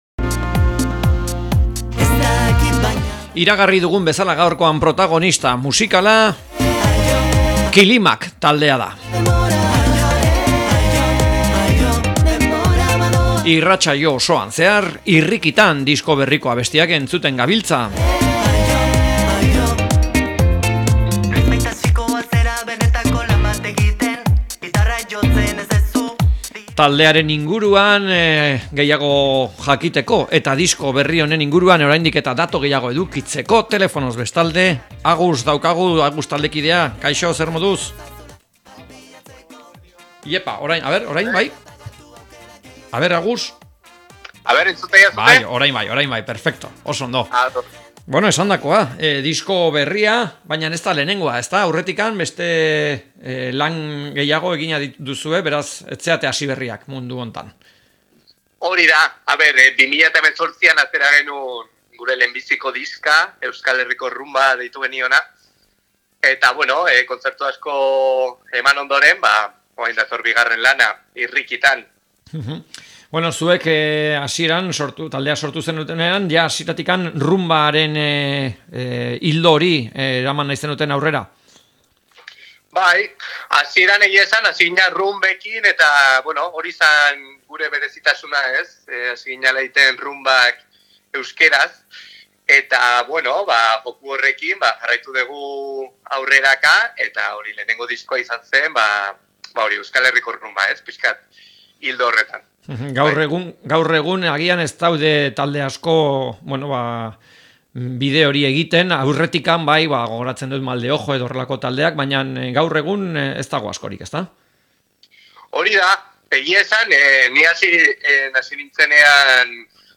Kilimak taldeari elkarrizketa